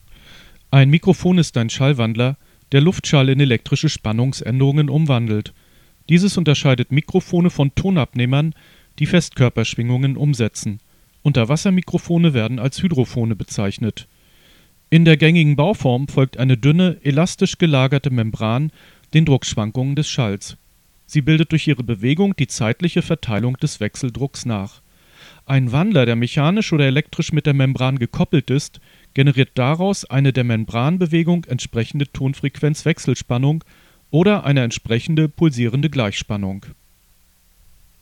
Im Folgenden habe ich Sprachaufnahmen von allen Mikrofonen, die ich besitze, aufgelistet.
Dynamisches Mikrofon der billigsten Sorte
Hier eine Aufnahme mit zusätzlichem Windschutz:
Die Anblasgeräusche beim Sprechen werden fast vollständig eliminiert.
CT329 Dynamisch mit Windschutz.mp3